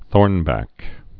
(thôrnbăk)